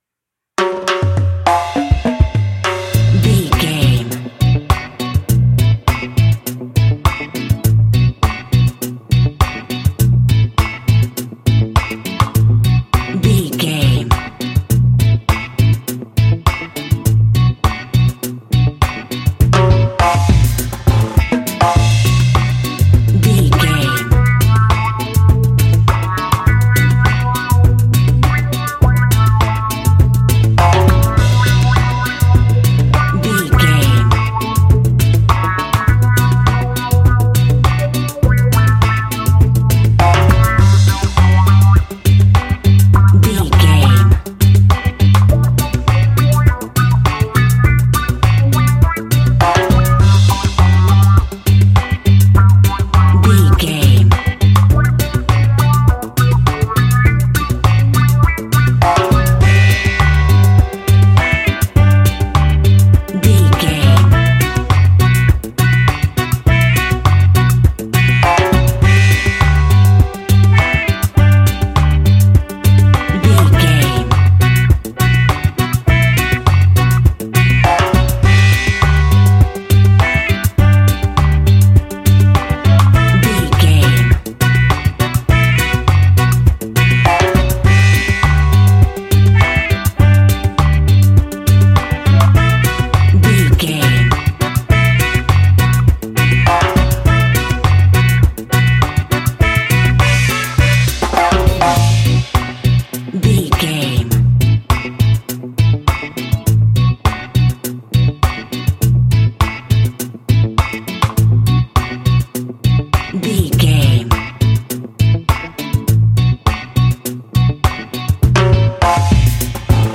Classic reggae music with that skank bounce reggae feeling.
Aeolian/Minor
dub
instrumentals
laid back
chilled
off beat
drums
skank guitar
hammond organ
percussion
horns